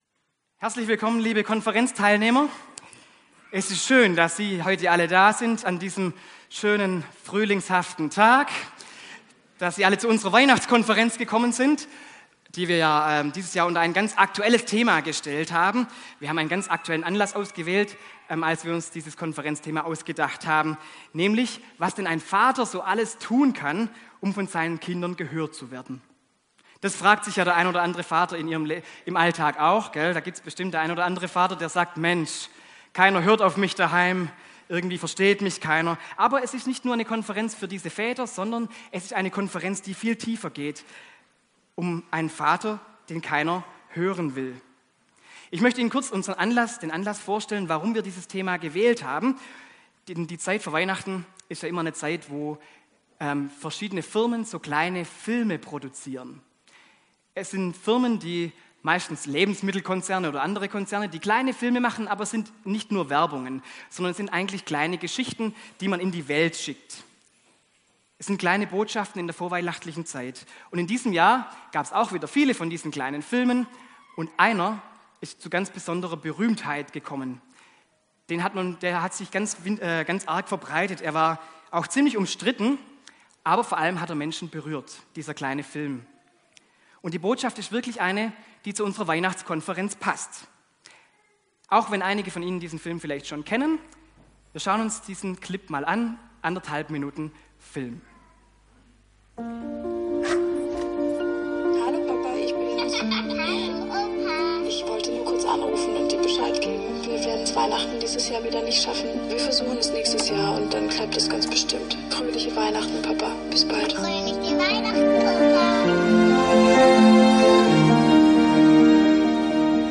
Gottesdienst an Heilig Abend mit Anspielteam.